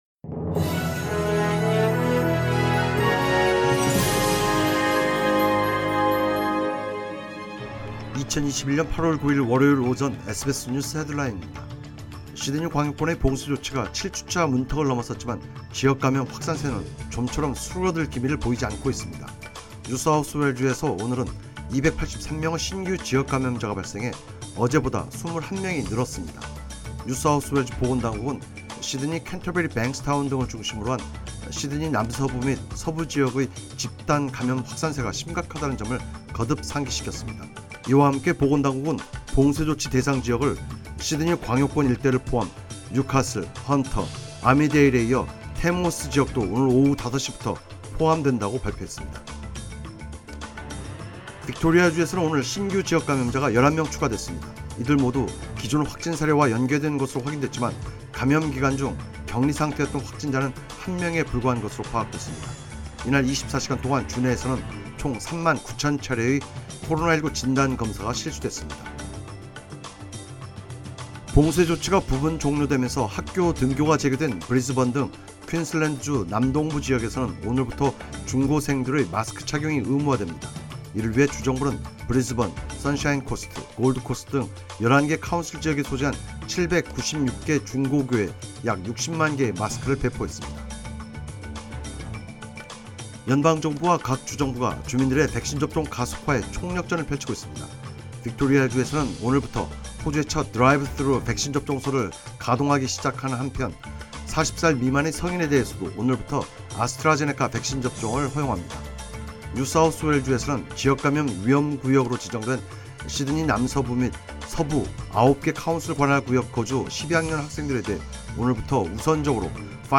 2021년 8월 9일 월요일 SBS 간추린 주요뉴스